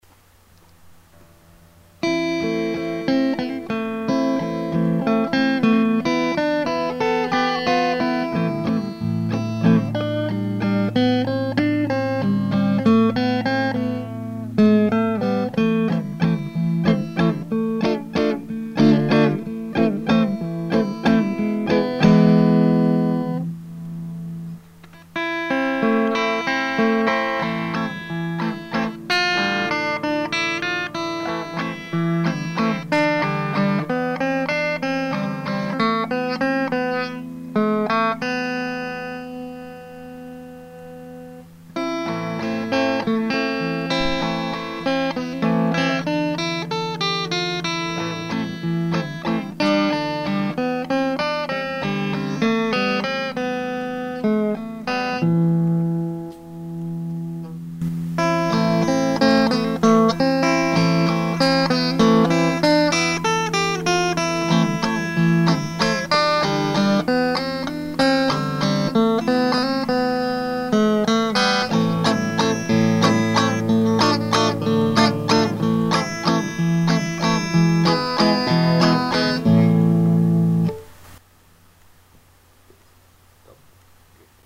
1- Micro manche seul de 0 à 20sec
2-Micro chevalet seul de 20 à 40 sec
5- entre les deux de 40 à 60sec
4 entre les deux en simple bobinages la fin
Le résultat sonore est très plaisant les différentes possibilités de réglages donnant des résultats bien différents cette guitare permet de jouer tous les styles.